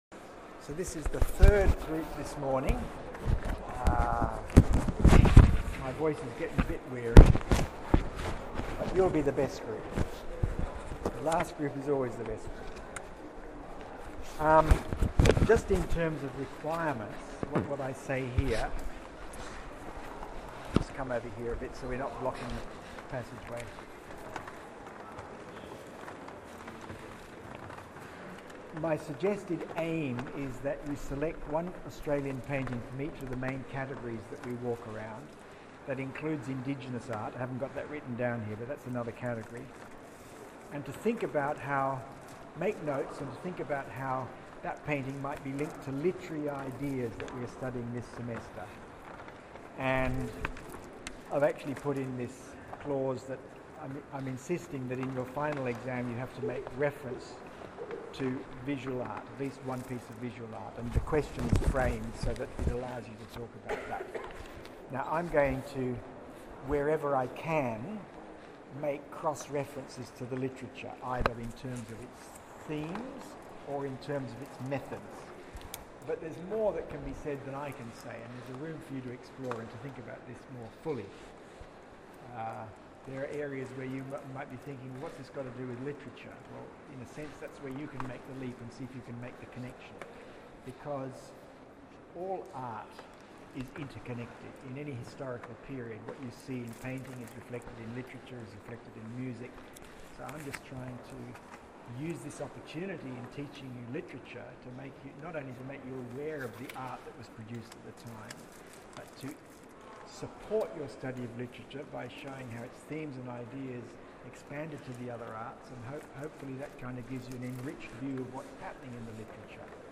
I have attached the audio talkl tour here…